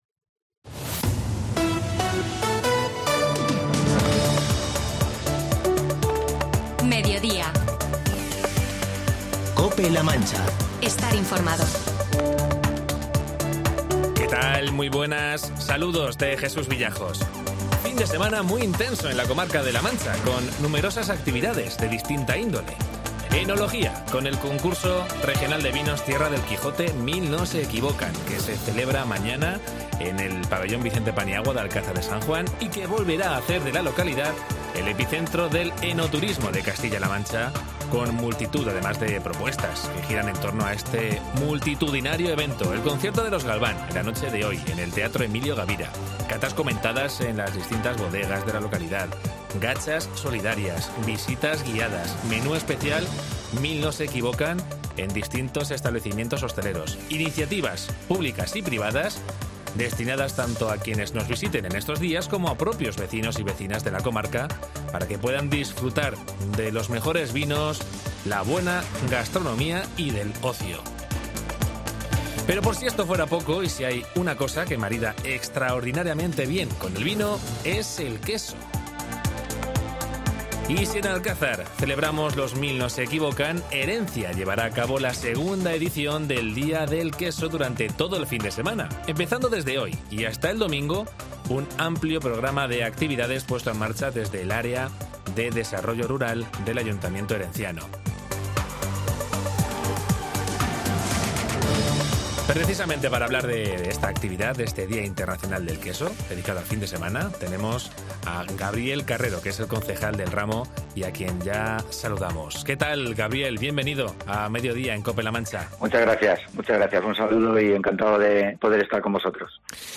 Entrevista a Gabriel Carrero, Concejal de Desarrollo Rural sobre el Día del Queso que se celebra en Herencia